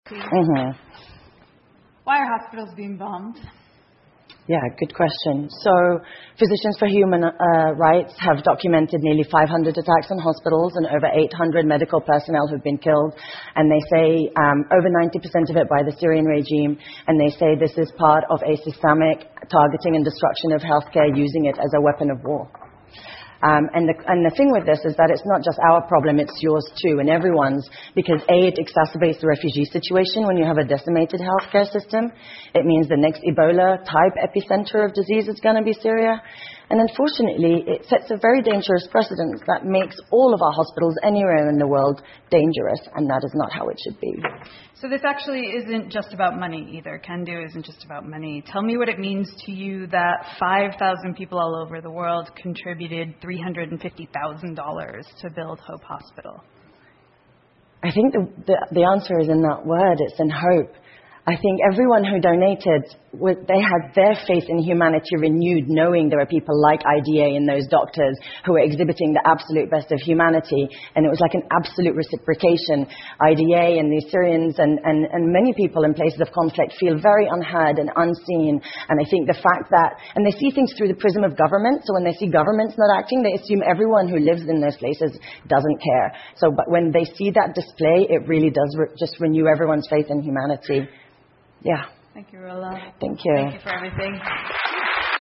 TED演讲:医生、护士和救援人员重建叙利亚() 听力文件下载—在线英语听力室